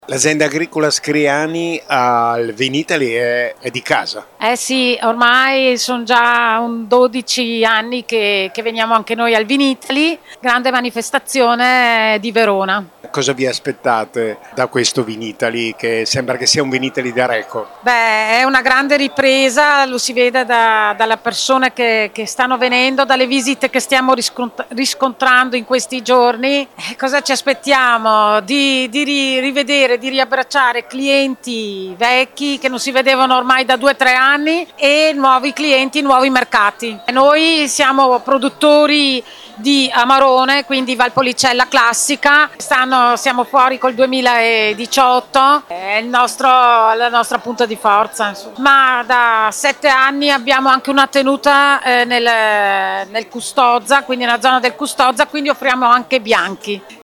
L’azienda Agricola Scriani della Valpolicella a Vinitaly2023